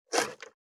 468,野菜切る,咀嚼音,ナイフ,調理音,まな板の上,料理,
効果音厨房/台所/レストラン/kitchen食器食材